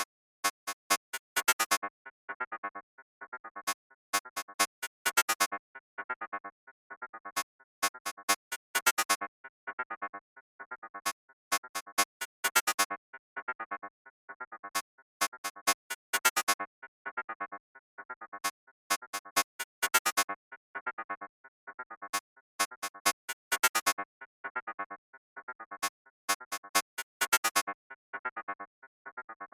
合成器环路延迟
描述：简单的合成器循环延迟。
Tag: 130 bpm Deep House Loops Synth Loops 4.97 MB wav Key : Unknown